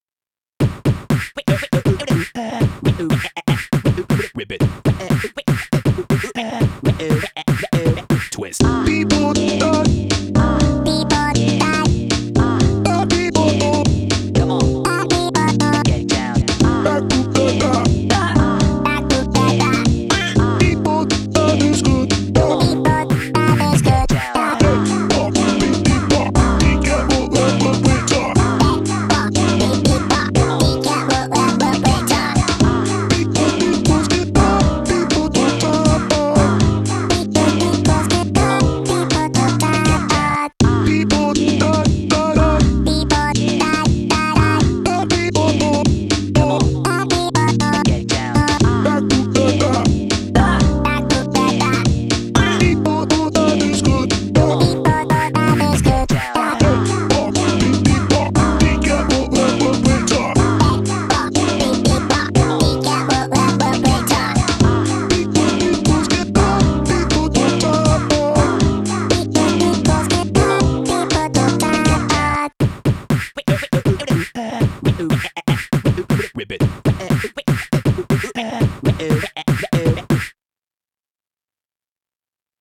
BPM120